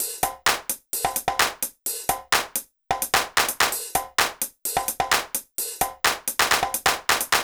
BAL Beat - Mix 7.wav